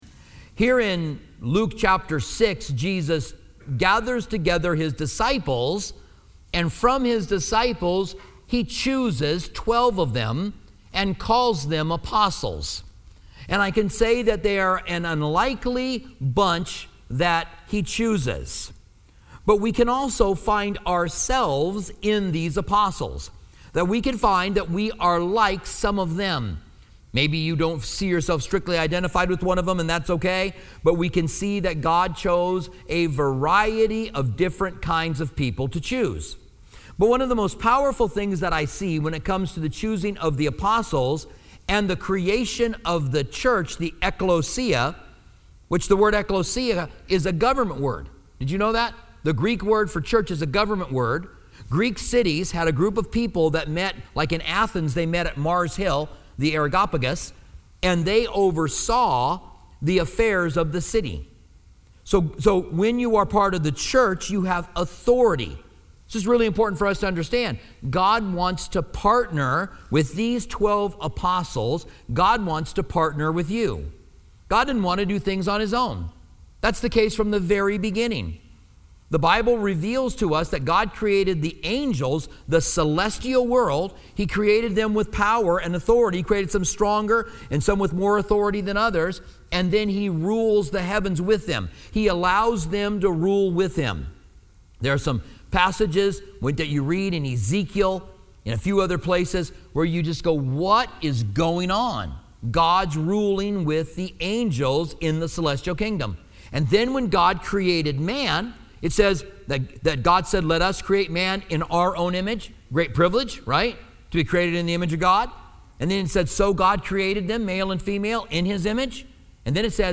giving an in depth message on the personalities of the apostles.